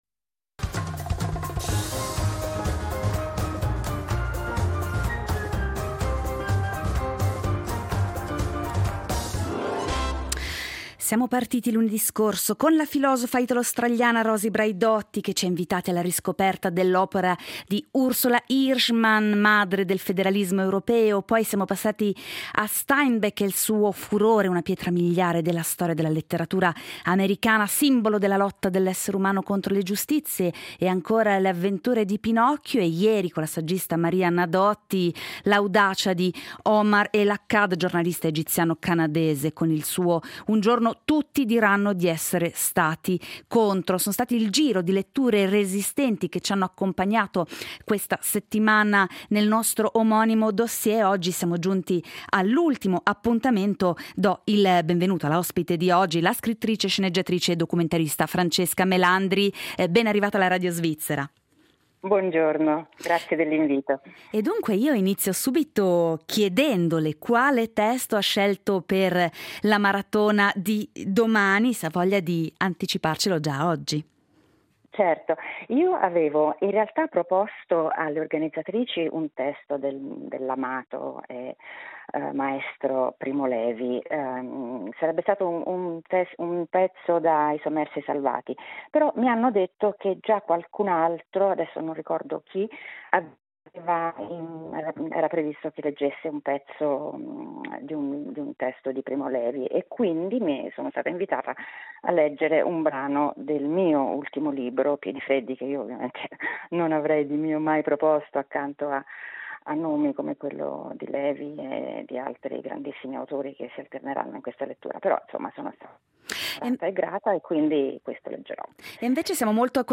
L’ospite di oggi è la scrittrice, sceneggiatrice e documentarista Francesca Melandri.